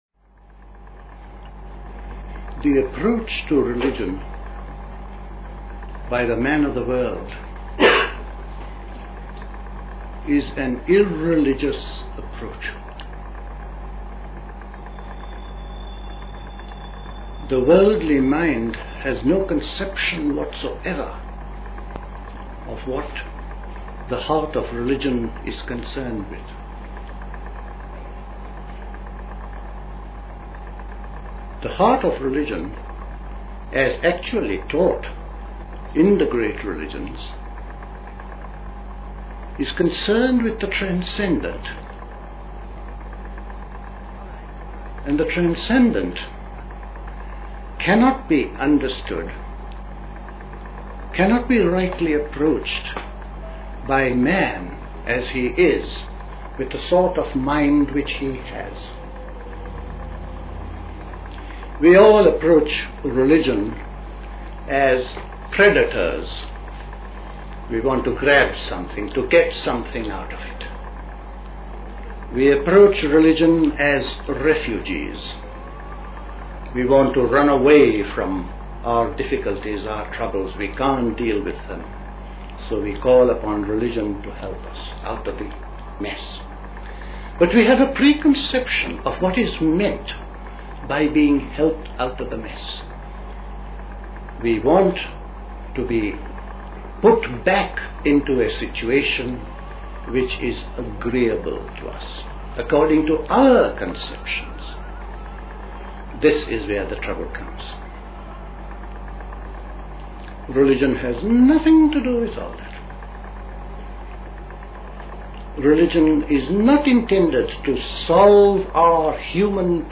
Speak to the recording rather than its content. at Dilkusha, Forest Hill, London on 8th June 1969